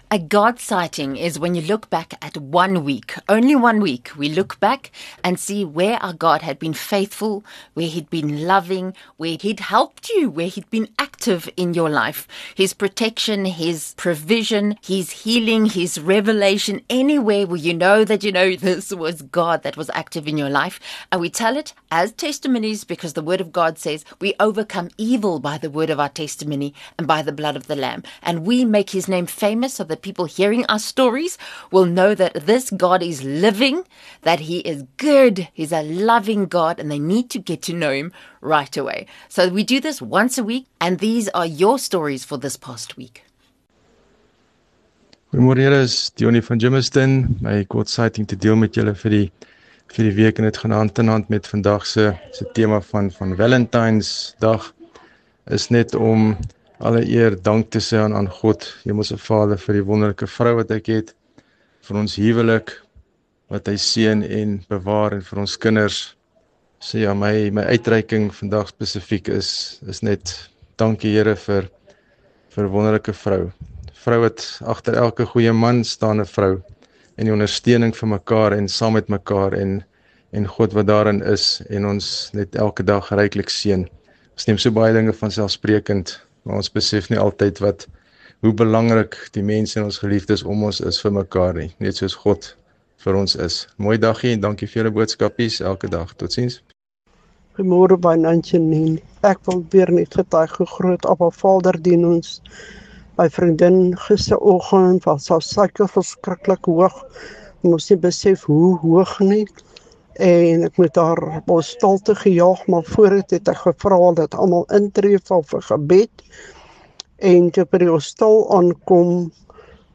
This morning drive programme offers you everything you need to start your day with Good News!